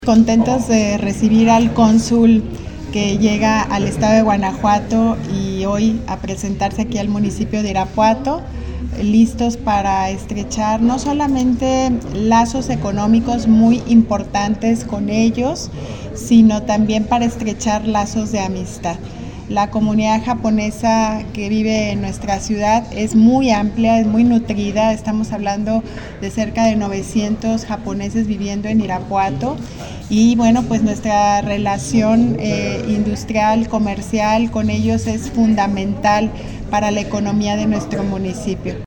AudioBoletines
Lorena Alfaro García, presidenta municipal de Irapuato